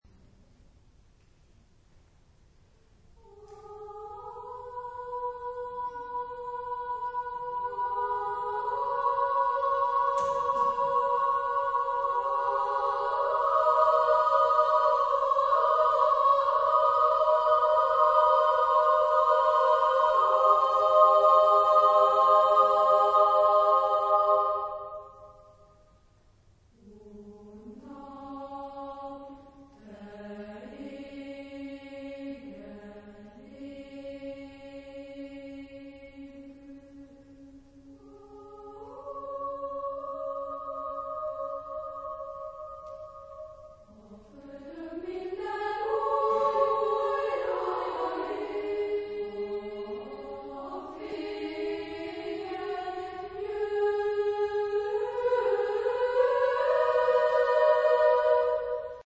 Genre-Style-Forme : Chœur ; Profane
Type de choeur : SSA  (3 voix égales )
Tonalité : do modal